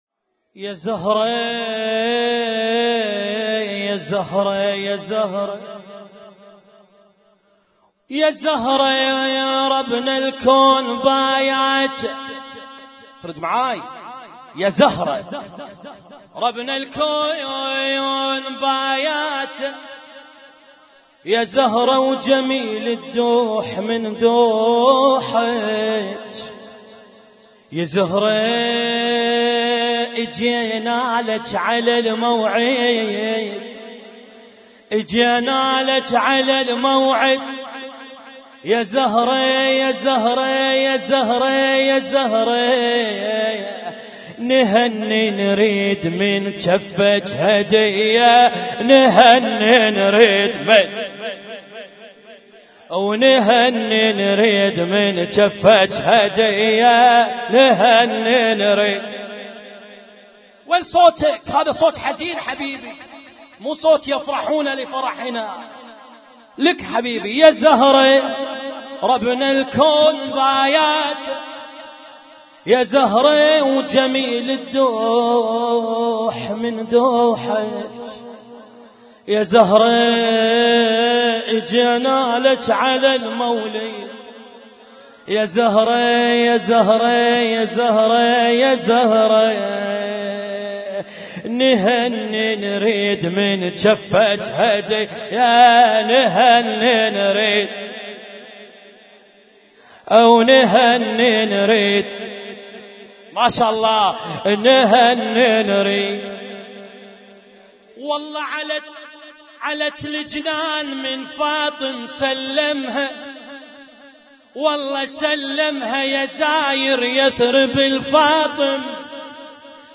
Husainyt Alnoor Rumaithiya Kuwait
اسم النشيد:: مولد الزهراء عليها السلام 1437